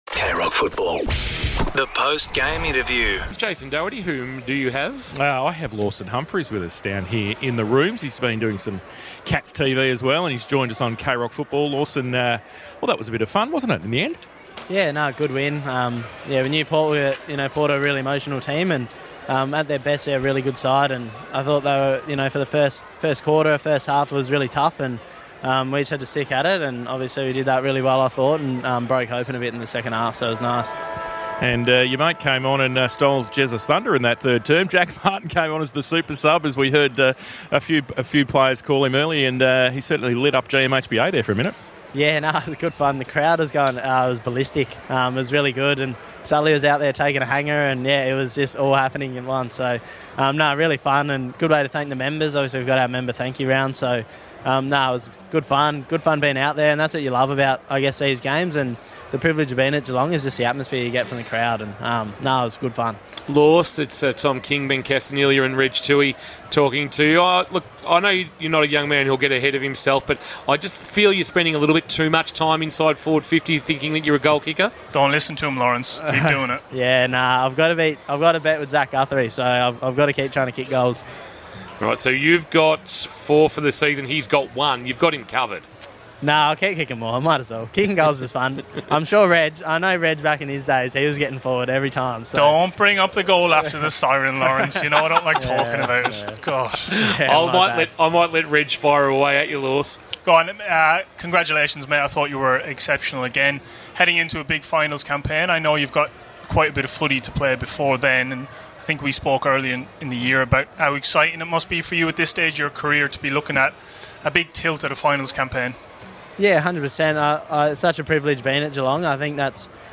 2025 - AFL - Round 21 - Geelong vs. Port Adelaide: Post-match interview